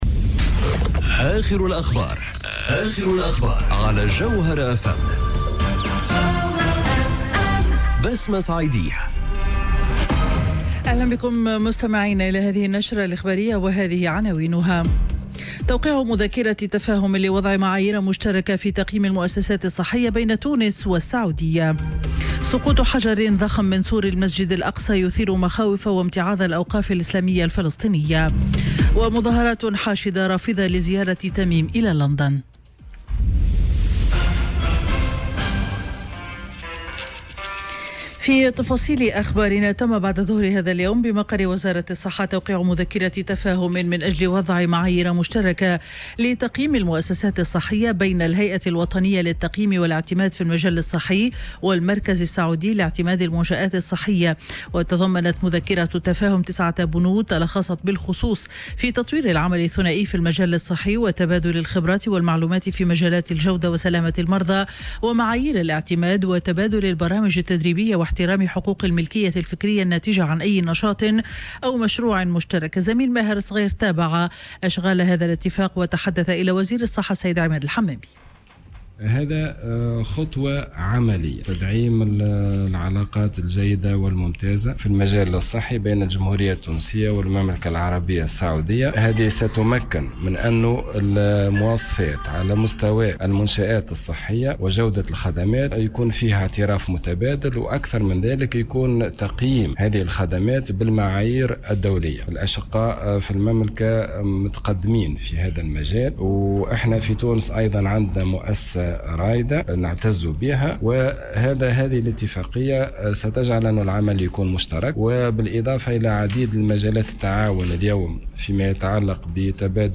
نشرة أخبار السابعة مساء ليوم الاثنين 23 جويلية 2018